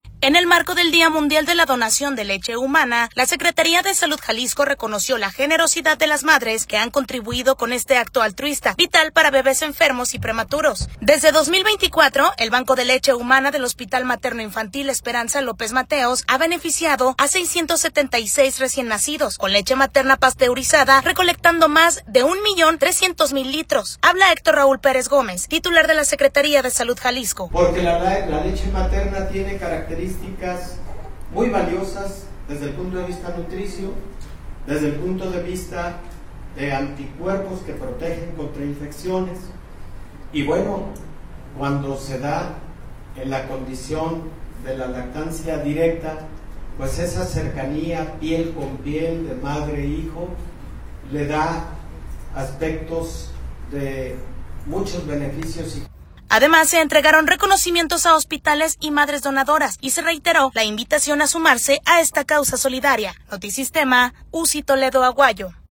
Habla Héctor Raúl Pérez Gómez, Titular de la Secretaría de Salud Jalisco.